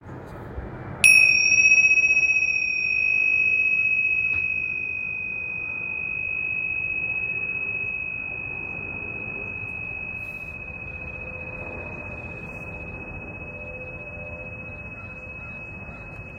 Tingsha Bells
Tingsha are two small metal cymbals stuck together to produce a clear and high-pitched tone. Different size tingsha produces different frequencies – large size tingsha emits a low-toned sound that vibrates for a long period whereas smaller size tingsha provides a high-pitched, pure sound.
70mm-tingsha.m4a